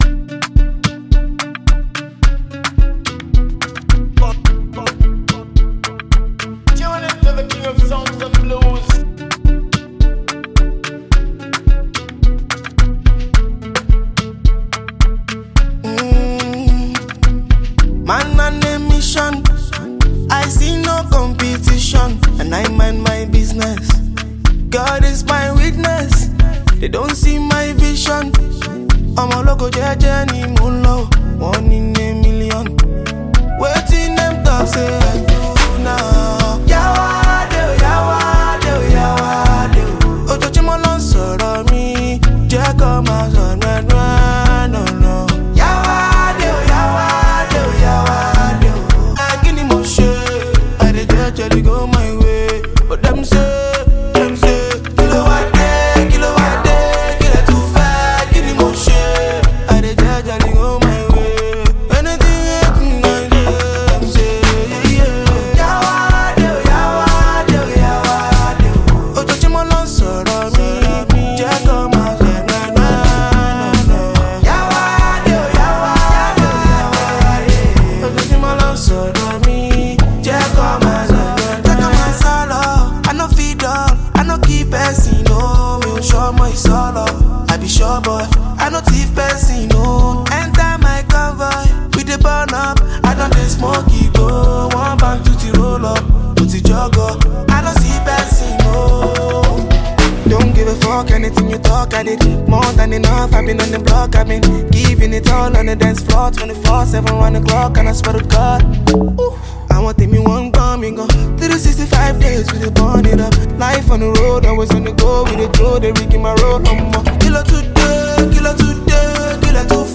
is an ear-catching song
melodious vocals